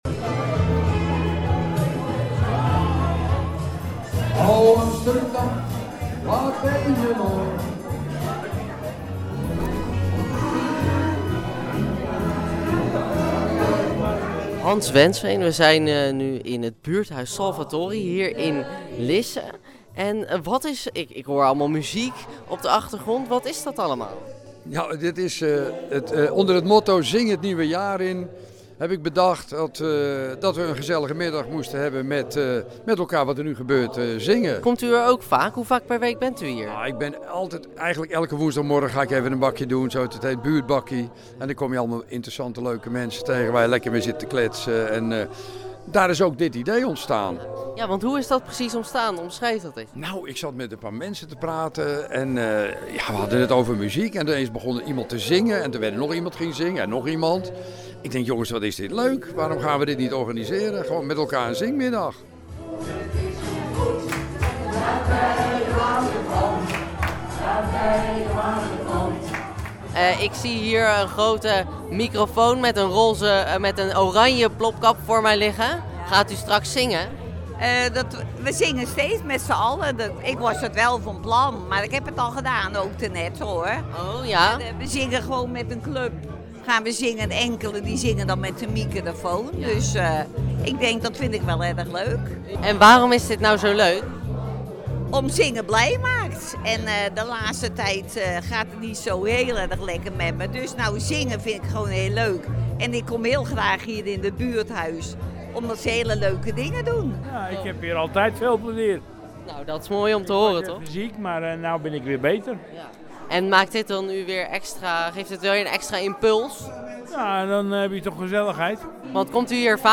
Een oranje gekleurde microfoon wordt na ieder liedje doorgegeven in de woonkamer van het buurthuis.
Een aantal weken later zit het hele buurthuis vol enthousiaste zestigplussers.